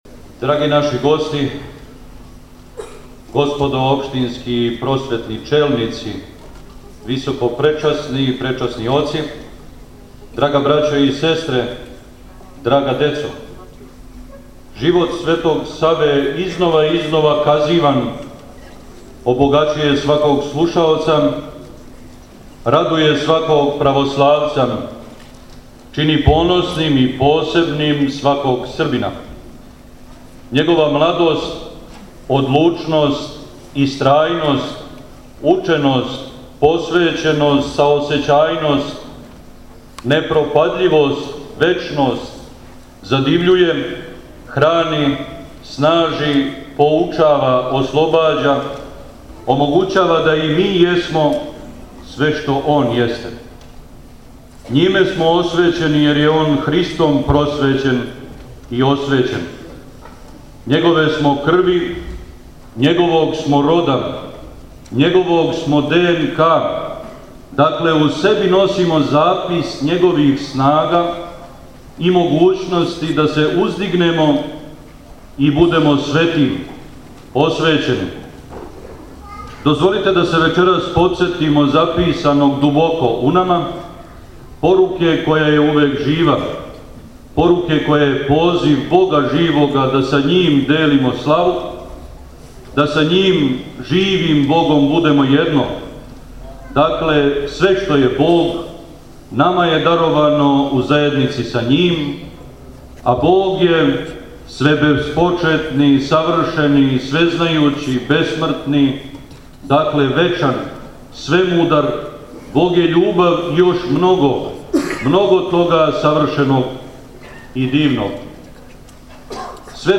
Одржана Светосавска академија у КЦ-у Рума
Свечана Светосавска академија одржана је уочи празника Светог Саве у Великој дворани Културног центра “Брана Црнчевић”, 26. јануара 2018. године, с почетком у 19 часова.